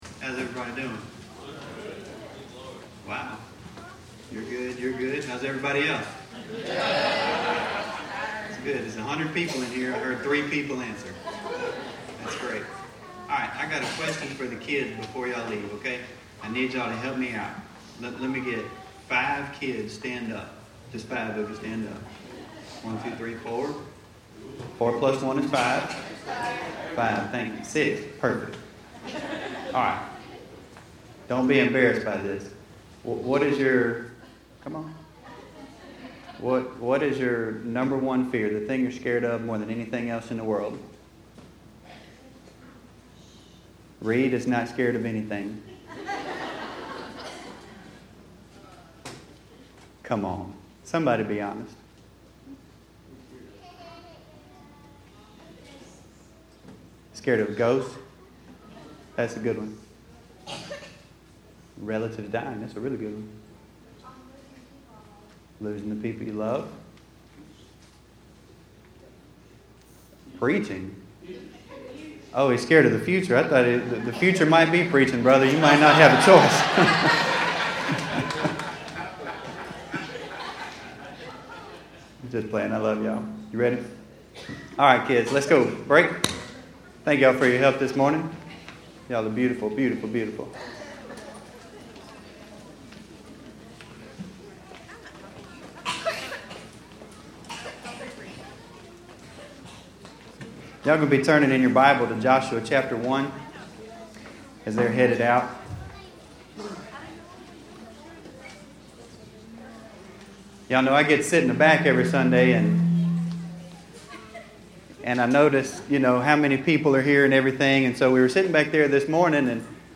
2_28_16_sermon.mp3